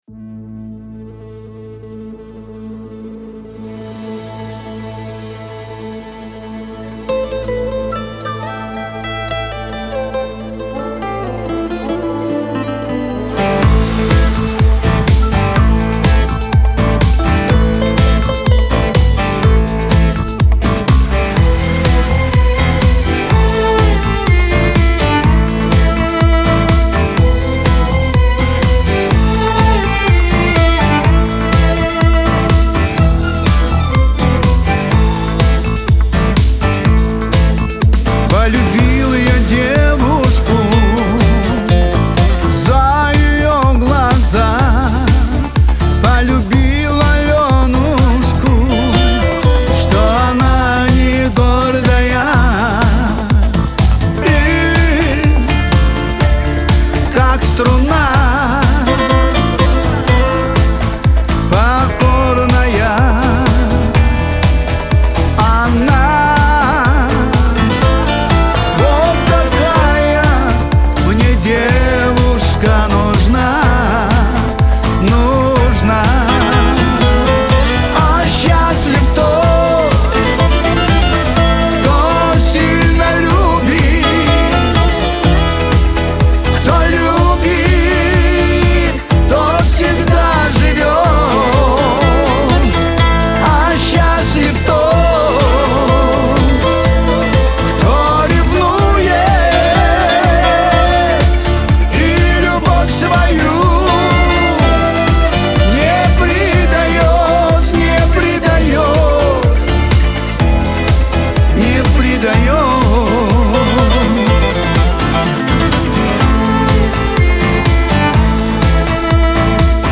Трек размещён в разделе Русские песни / Шансон.